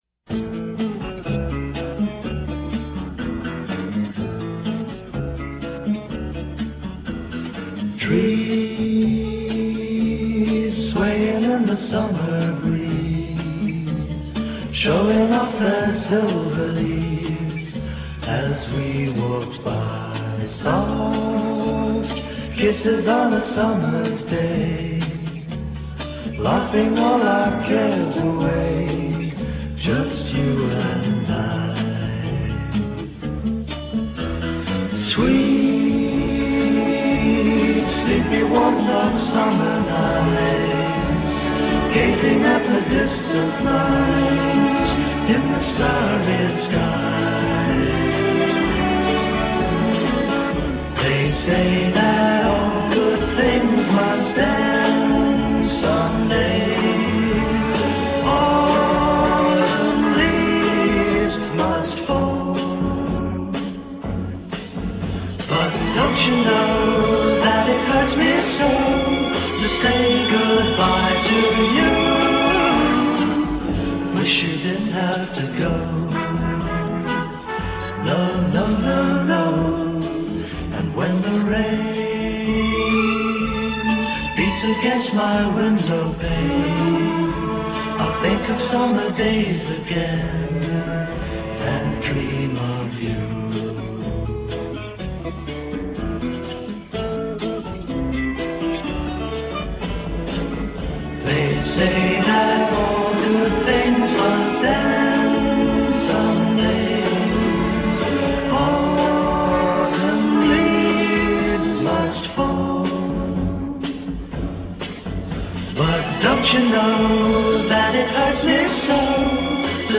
THESE SOUNDS ARE IN REALAUDIO STEREO!